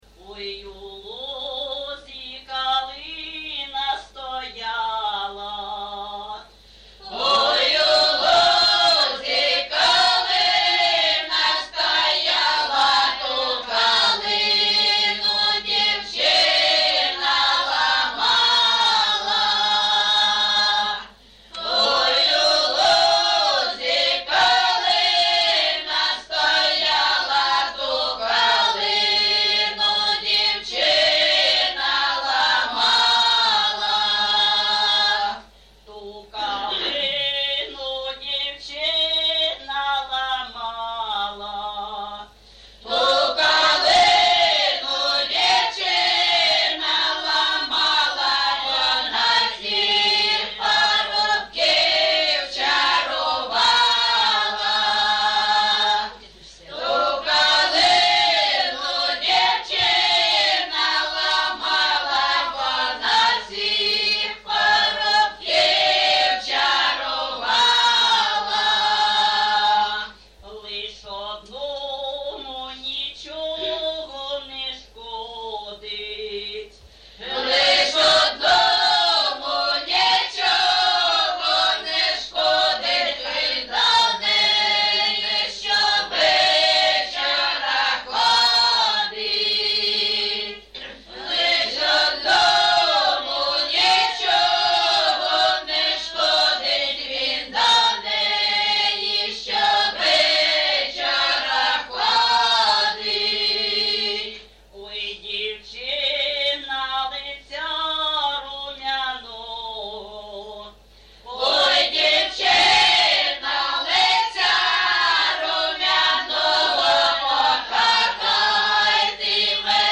ЖанрПісні з особистого та родинного життя
Місце записус-ще Щербинівка, Бахмутський район, Донецька обл., Україна, Слобожанщина